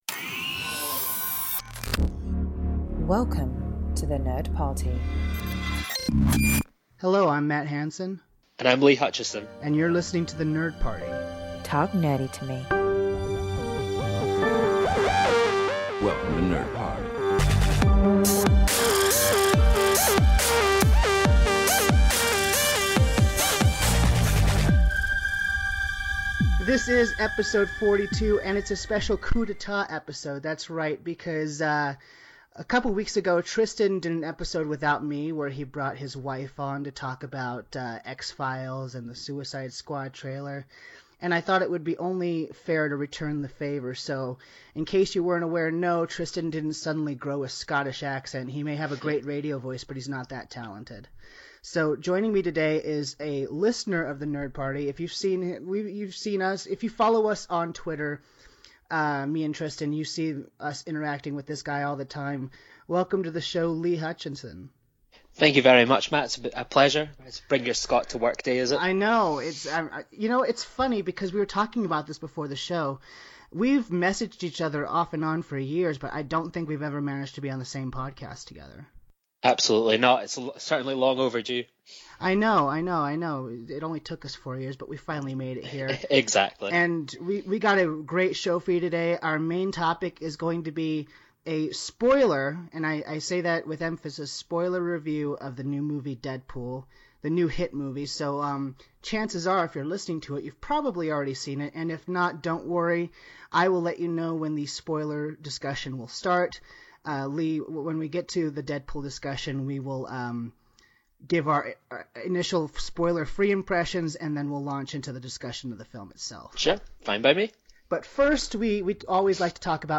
*Recorded via Skype